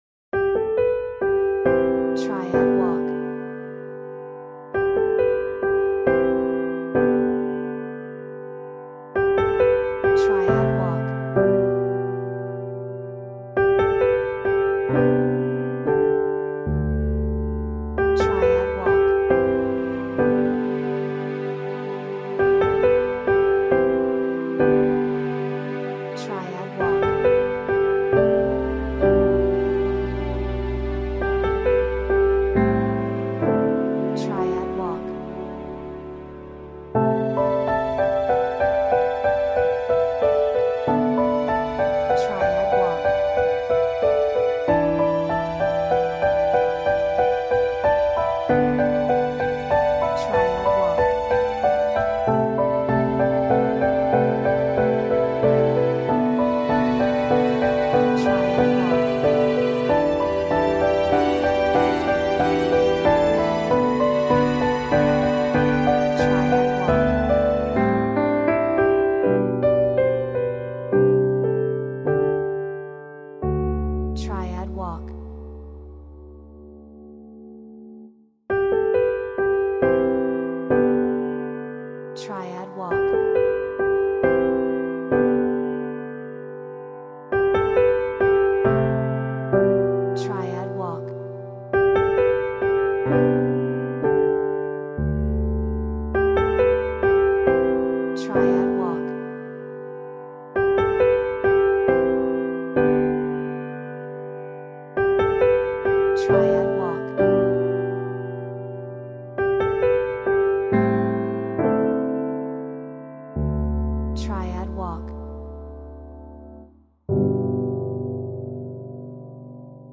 Orchestral , ノスタルジック , Ambient , Healing